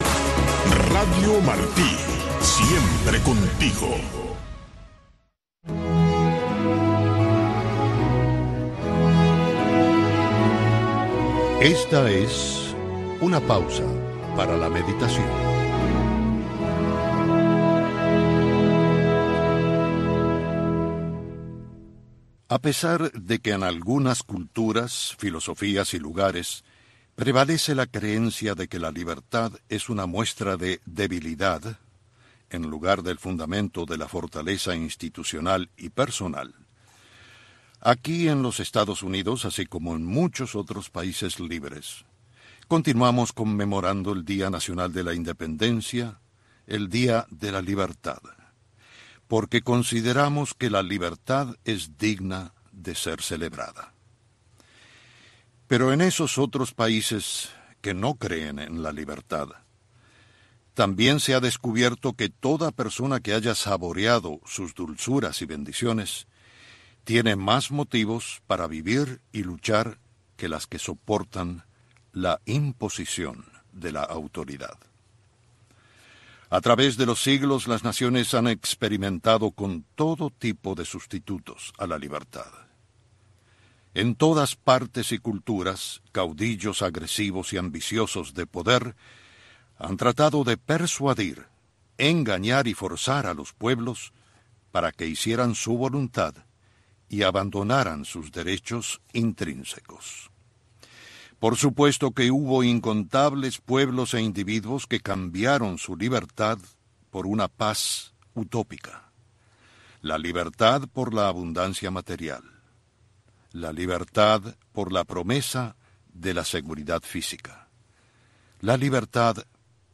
Revista informativa con noticias, eventos, blogs cubanos, efemérides, música y un resumen de lo más importante de la semana en el mundo del arte.